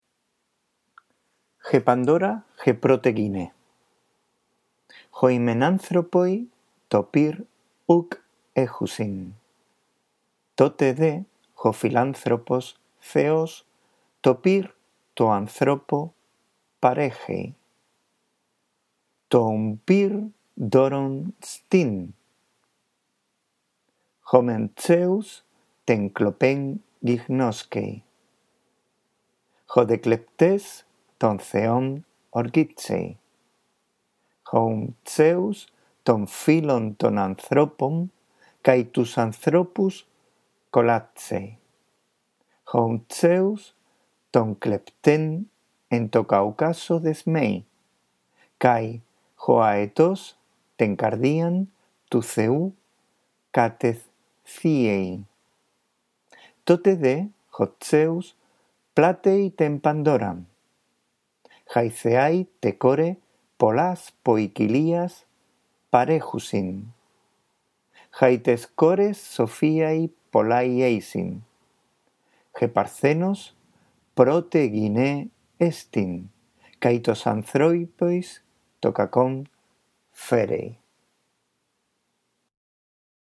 Presta atención a la narración en griego de este mito.